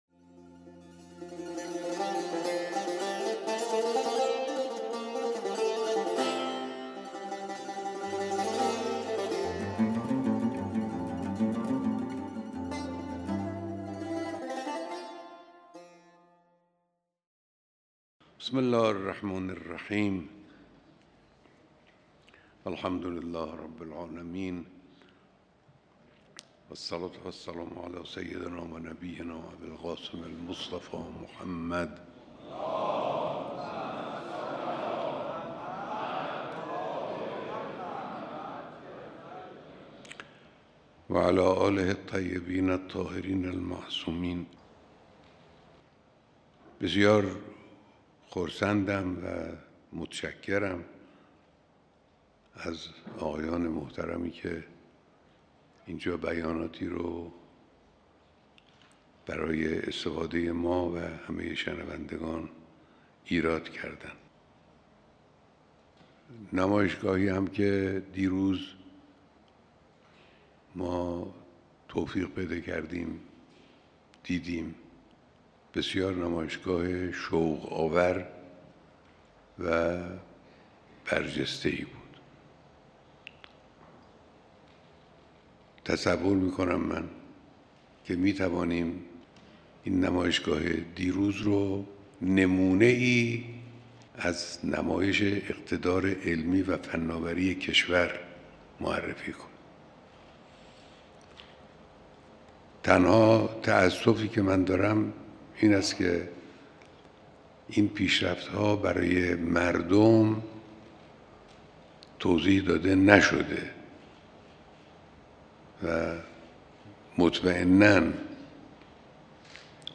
بیانات در دیدار فعالان اقتصادی و تولیدکنندگان